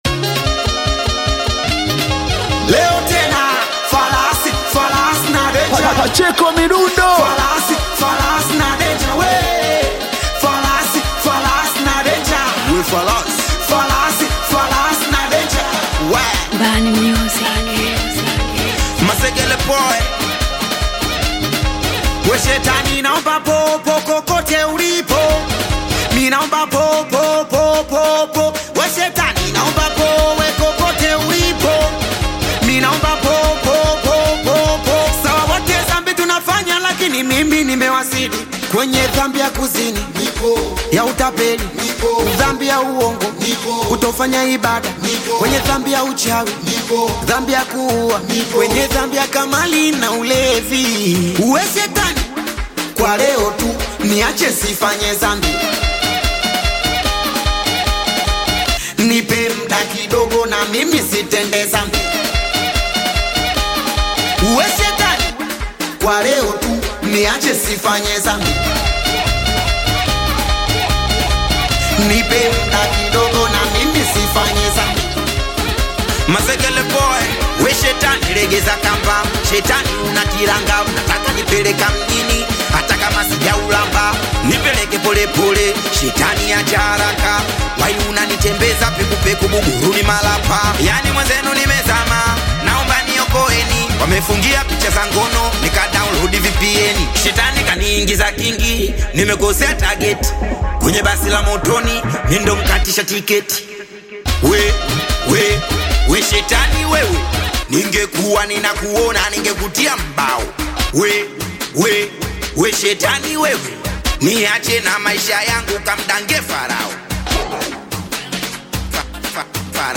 Singeli song